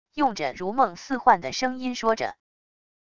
用着如梦似幻的声音说着wav音频